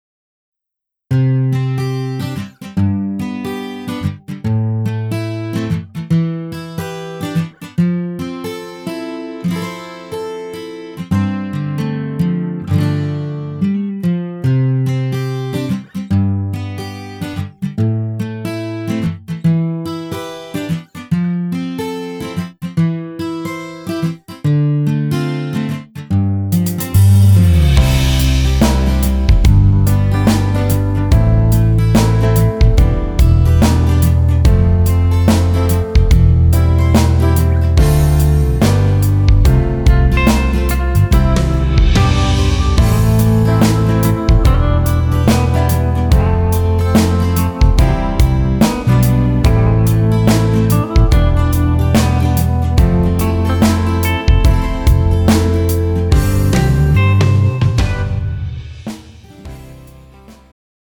음정 원키
장르 축가 구분 Pro MR